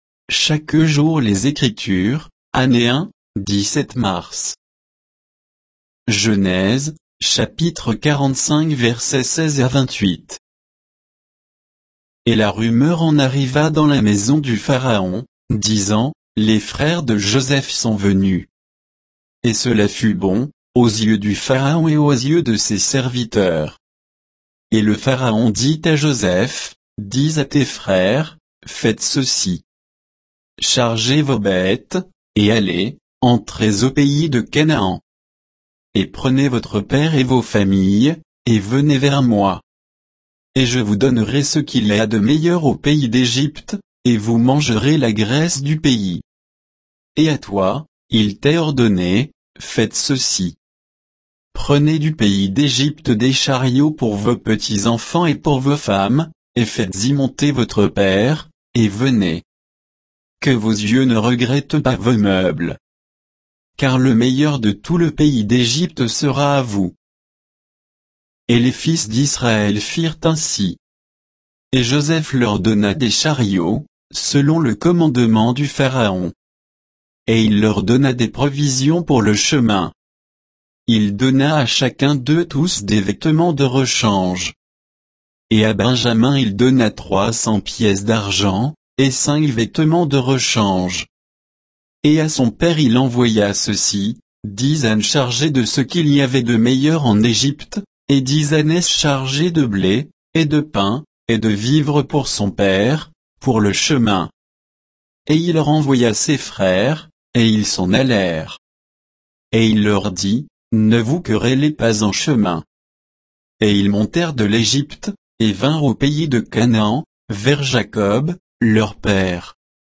Méditation quoditienne de Chaque jour les Écritures sur Genèse 45, 16 à 28